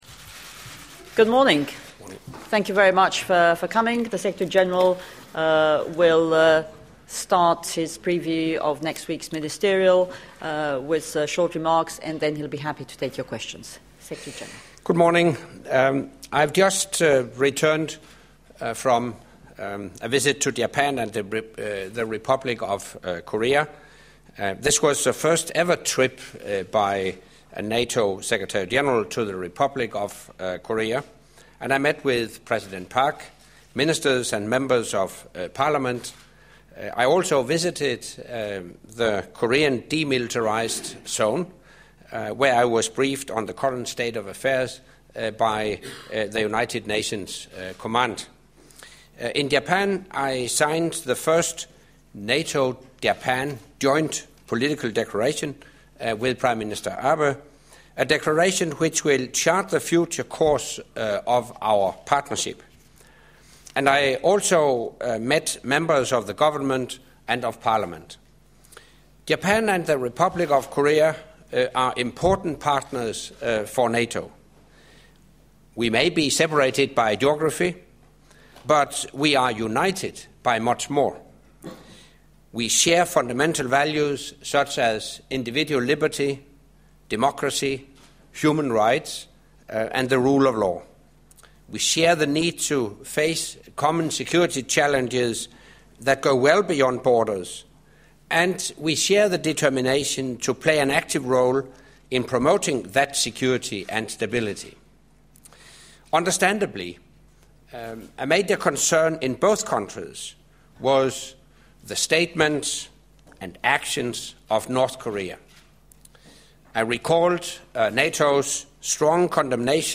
NATO Secretary General briefs the press on April Foreign Ministerial
NATO Secretary General Anders Fogh Rasmussen briefed the press on Friday ( 19 April 2013) on his recent visit to South Korea and Japan and the upcoming NATO Foreign Ministers meeting on 23 April.
Pre-ministerial press conference by NATO Secretary General Anders Fogh Rasmussen